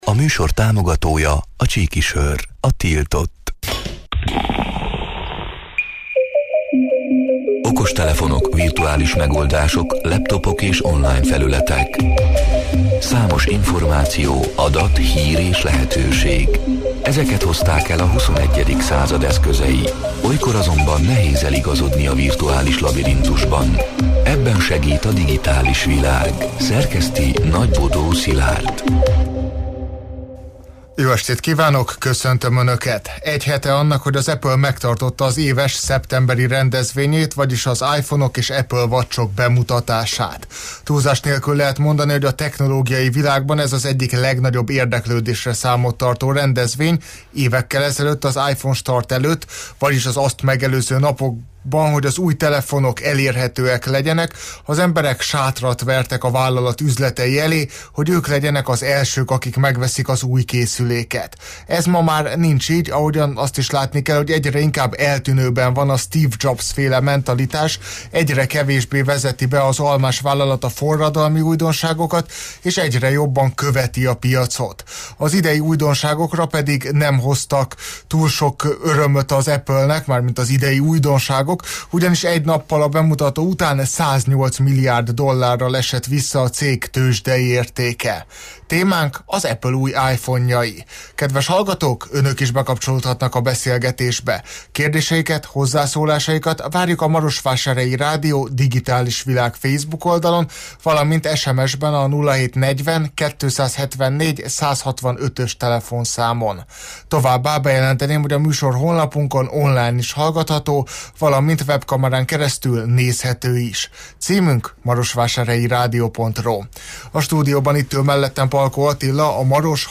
A Marosvásárhelyi Rádió Digitális Világ (elhangzott: 2025. szeptember 16-án, kedden este nyolc órától élőben) c. műsorának hanganyaga: